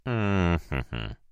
Звук раздумья мужчины перед выбором